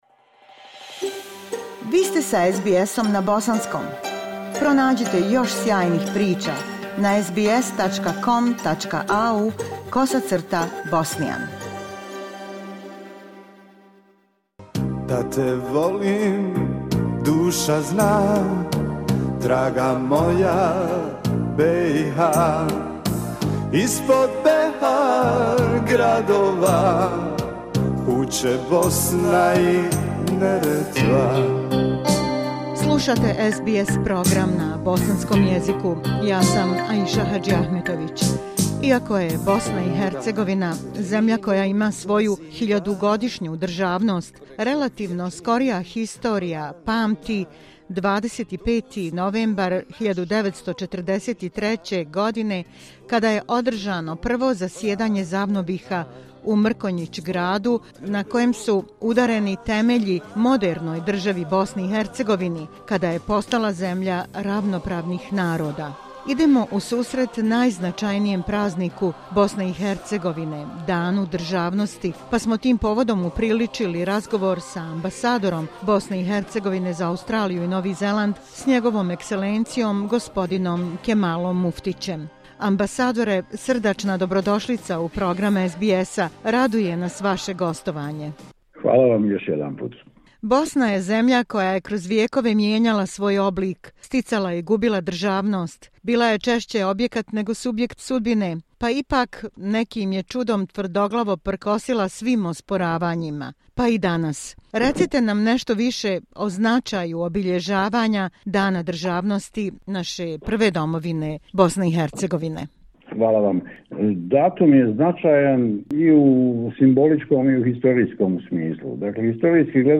Iako je BiH zemlja koja ima svoju hiljadugodišnju državnost, relativno skorija historija pamti 25. novembar 1943. kada je održano Prvo zasjedanje ZAVNOBiHA u Mrkonjić Gradu na kojem su udareni temelji modernoj državi BiH i na kojem je Prva domovina postala zemlja ravnopravnih naroda. Idemo u susret najznačajnijem prazniku BiH - Danu državnosti, pa smo tim povodom upriličili razgovor sa ambasadorom BiH za Australiju i Novi Zeland, s NJ. E. gospodinom Kemalom Muftićem.